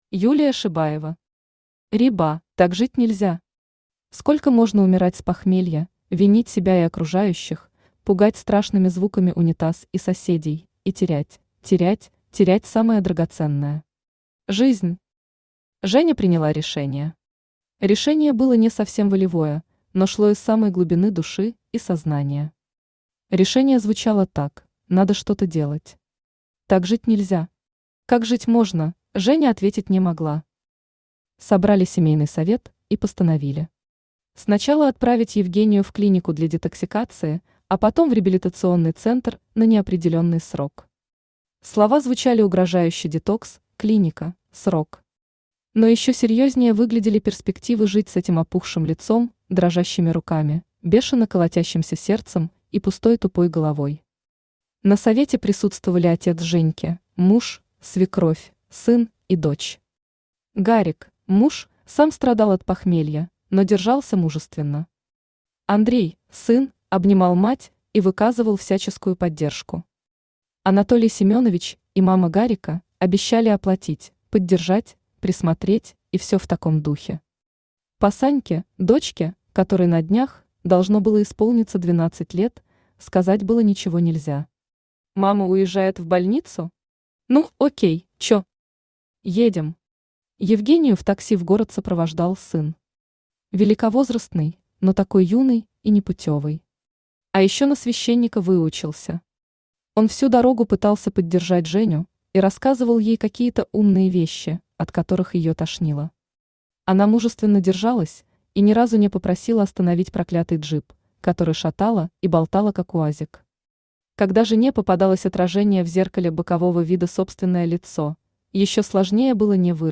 Аудиокнига Реба | Библиотека аудиокниг
Aудиокнига Реба Автор Юлия Шибаева Читает аудиокнигу Авточтец ЛитРес.